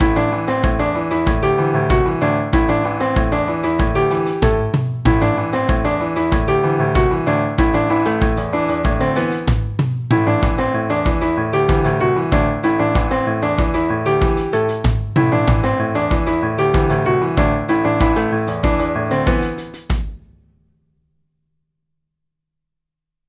不協和音は仕方なし・・・わからん